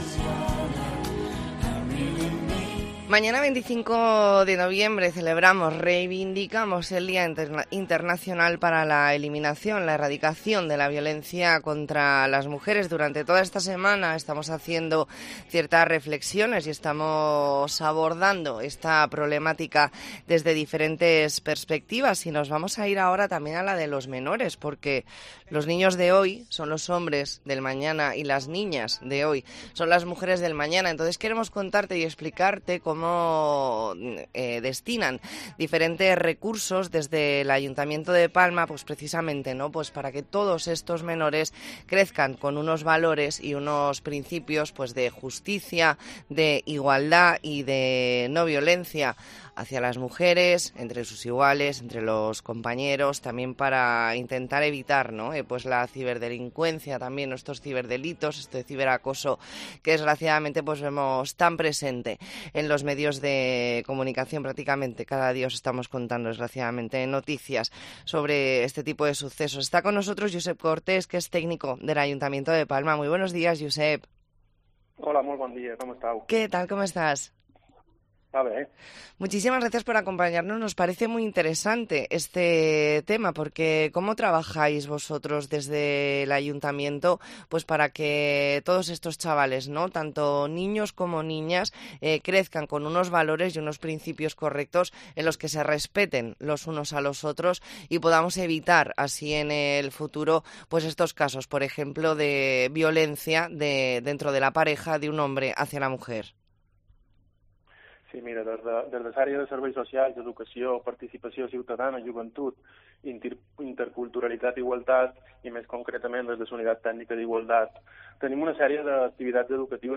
Entrevista en La Mañana en COPE Más Mallorca, viernes 24 de noviembre de 2023.